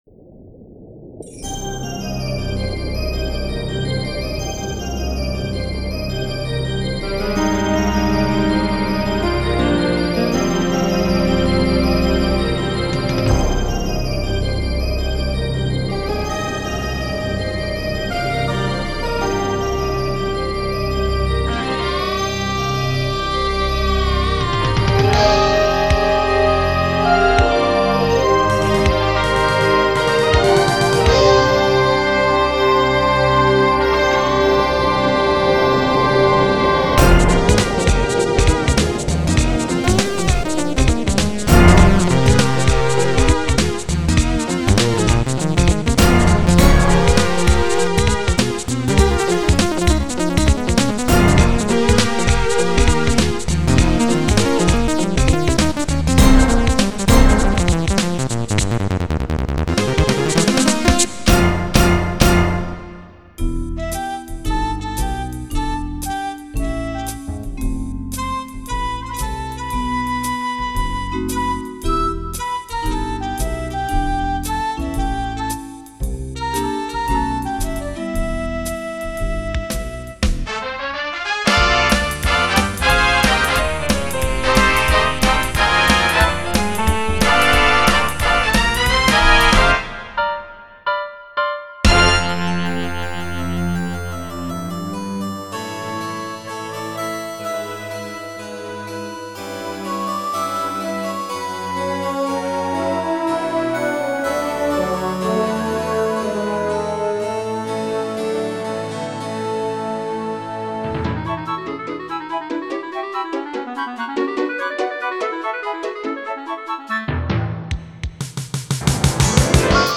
BPM12-288
Audio QualityPerfect (High Quality)